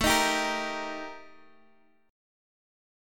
Listen to Eb/A strummed